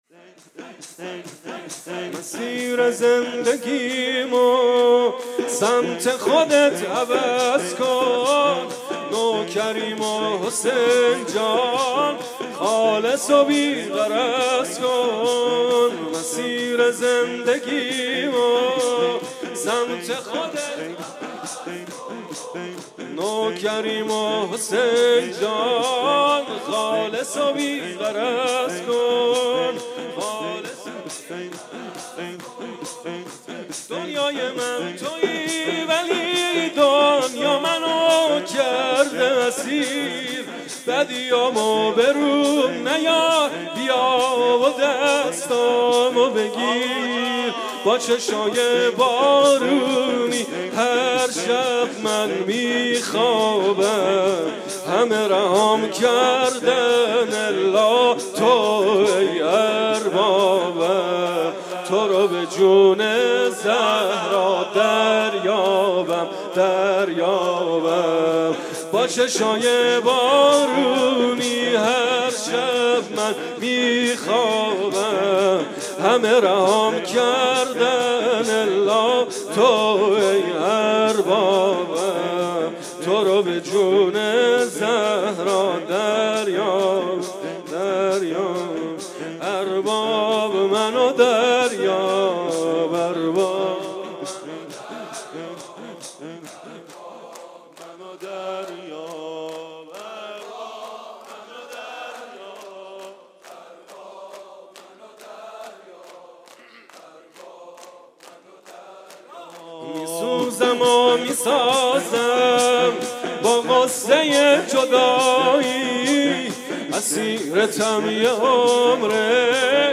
شب نوزدهم رمضان 98 - شور